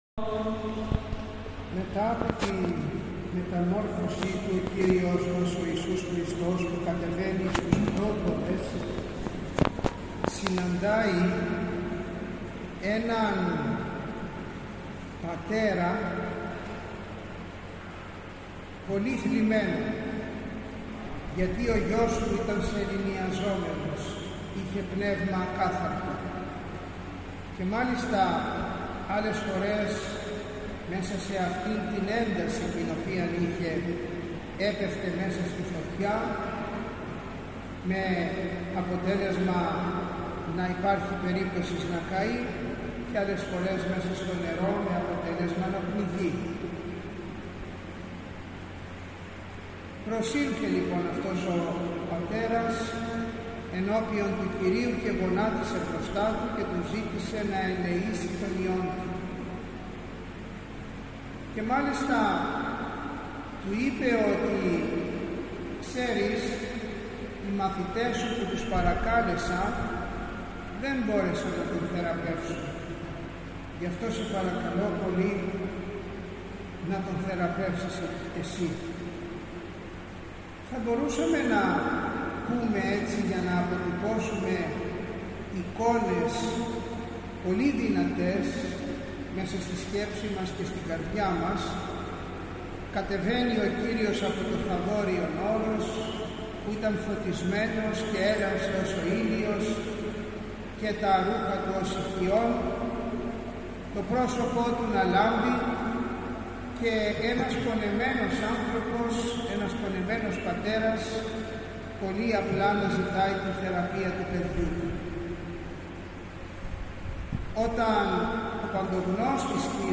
Την Κυριακή 17 Αυγούστου 2025 ο Σεβασμιώτατος Μητροπολίτης Θεσσαλιώτιδος και Φαναριοφερσάλων κ. Τιμόθεος χοροστάτησε στην Ακολουθία του Όρθρου και τέλεσε την Θεία Λειτουργία στον Ιερό Ναό Αγίου Νικολάου Καρδίτσης.